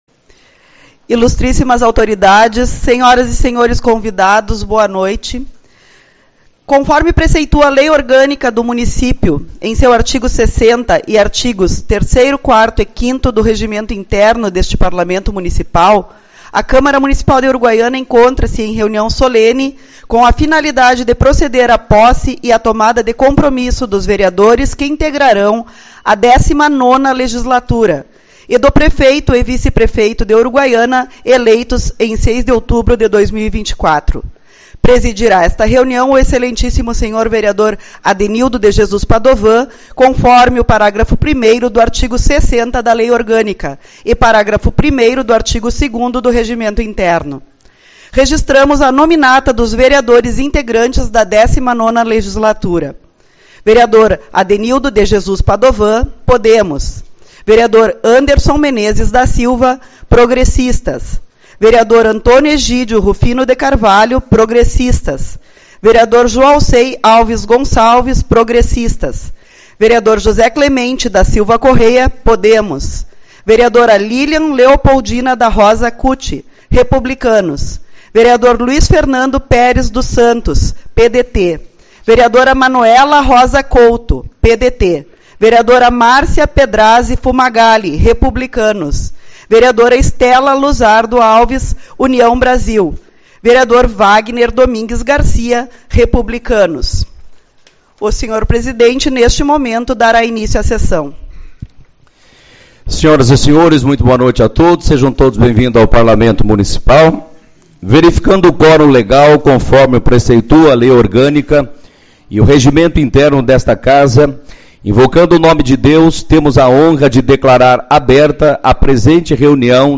Posse da Legislatura 2025/2028